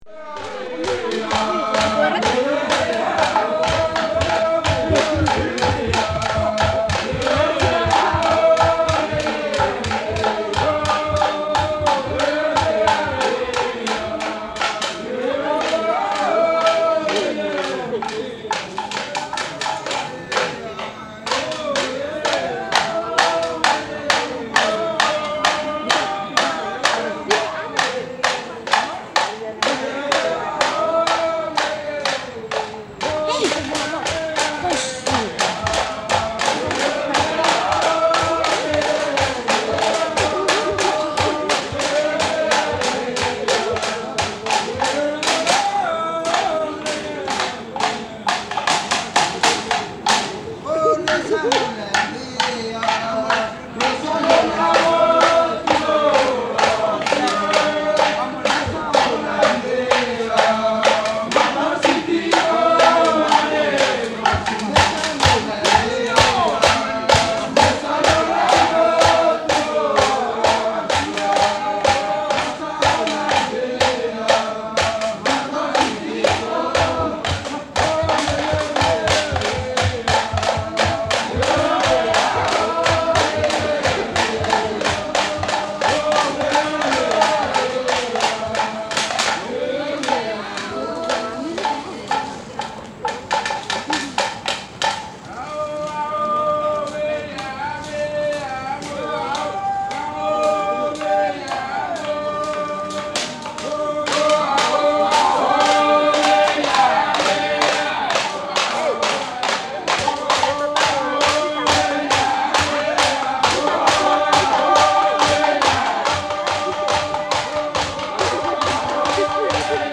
Durei-na-mbwe song (late evening performance).
reel-to-reel tape recordings of music and soundscapes
mainly on the island of Malekula (Malampa Province) in Vanuatu between 1960 and 1979.